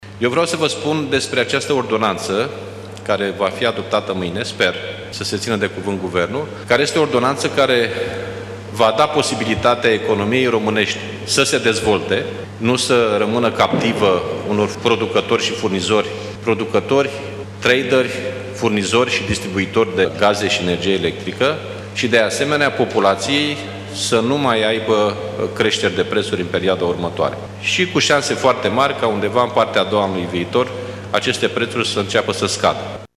Preşedintele PSD, Liviu Dragnea, a declarat azi că ordonanţa de urgenţă cu privire la măsurile fiscale anunţate de ministrul Finanţelor va fi dată probabil mâine, într-o şedinţă de Guvern: